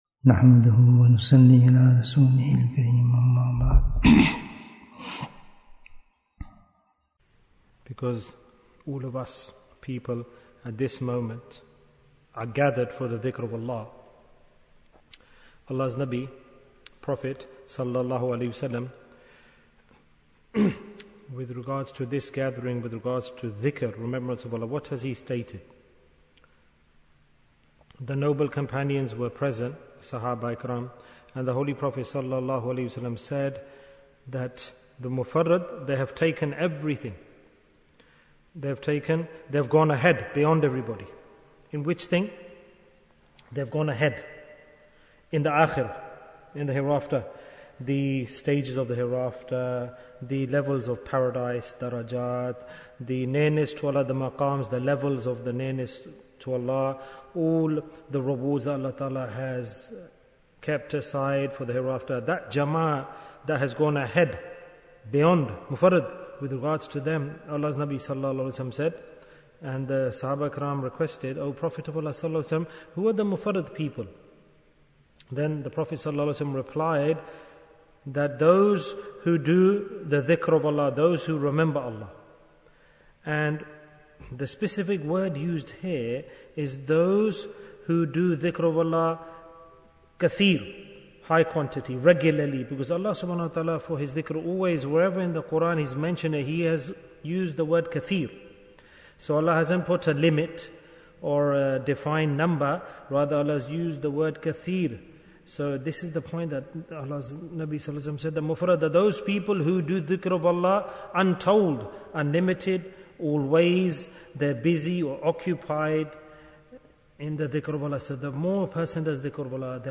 The Sign for the Love of Allah Bayan, 12 minutes21st June, 2023